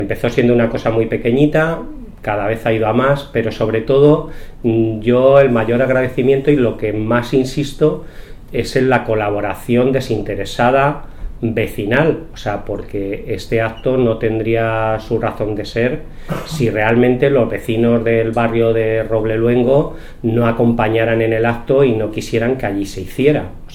El alcalde de Campillo de Ranas, Francisco Marato, habla de la media maratón de montaña del Ocejón.